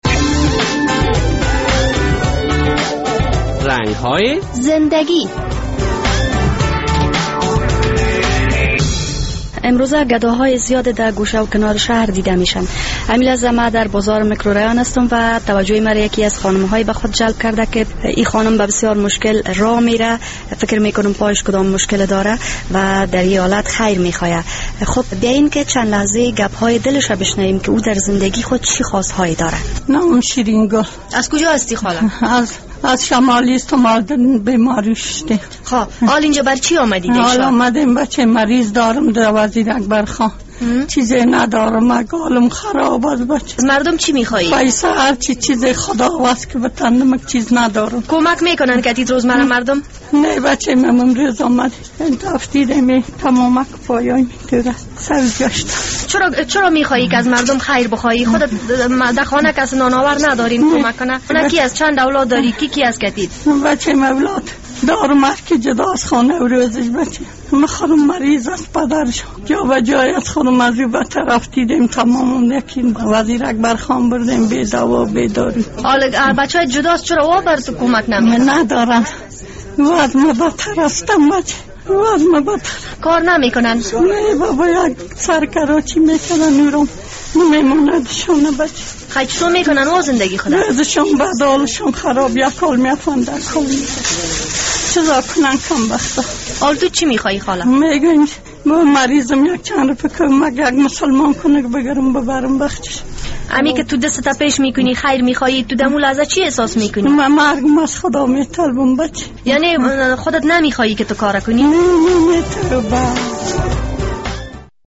در این برنامهء در رنگ های زنده گی با خانم کهن سالی صحبت شده که از اثر فقر و ناداری بالای یکی از سرک های کابل دست گدایی دراز کرده و منتظر است تا هموطنان توانمند اش با وی کمک کنند...